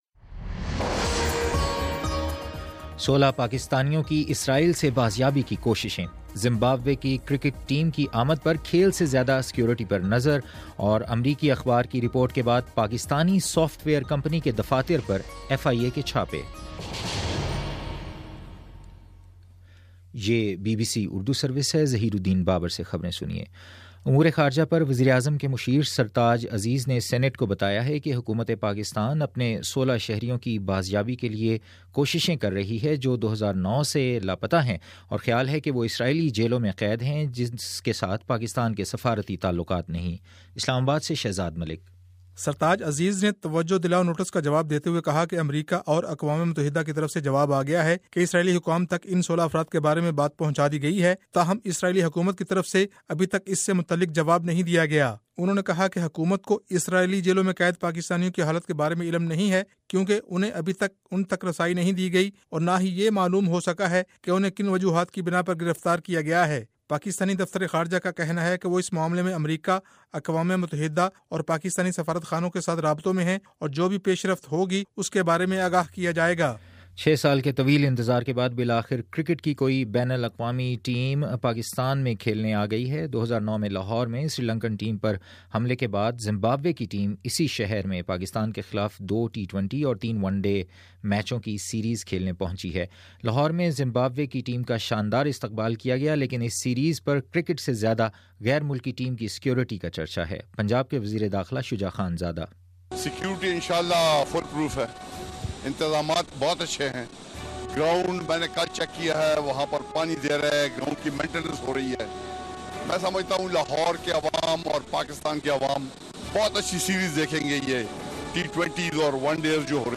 مئی 19: شام پانچ بجے کا نیوز بُلیٹن